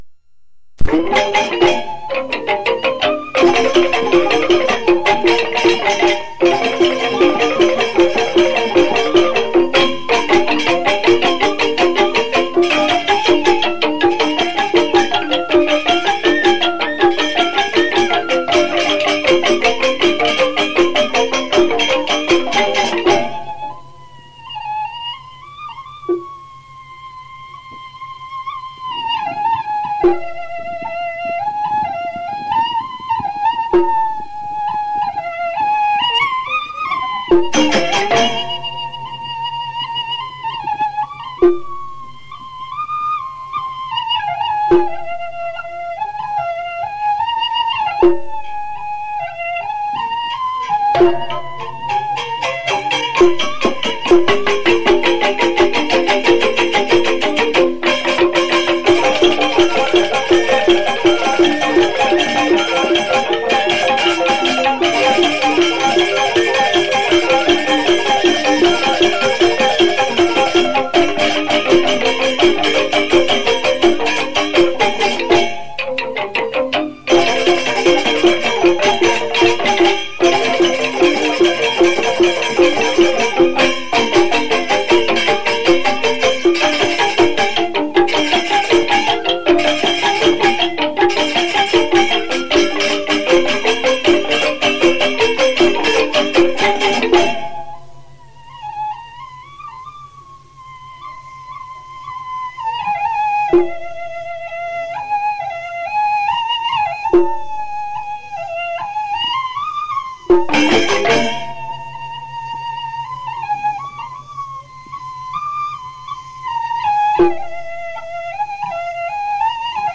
バリ島の大衆娯楽音楽“ジョゲッ”の音源！“竹製楽器“リンディック”に歌や笛を交えたアンサンブルが◎！